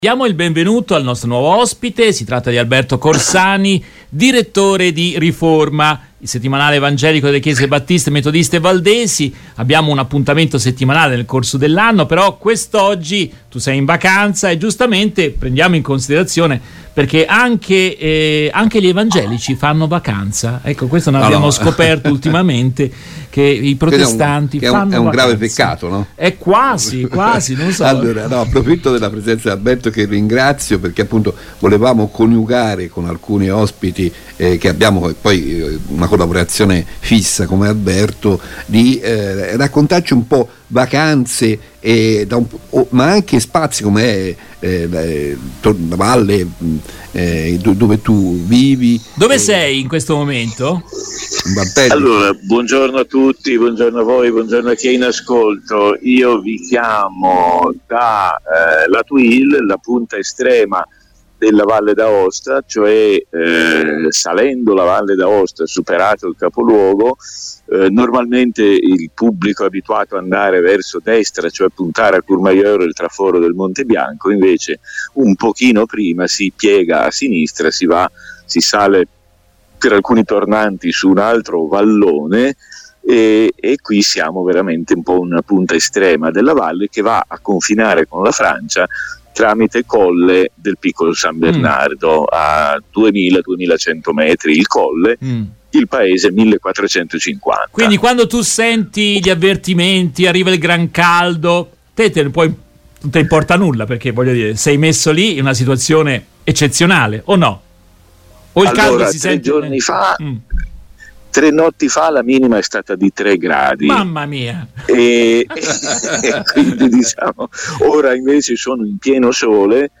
Venerdi 11 agosto nel corso della Diretta del Mattino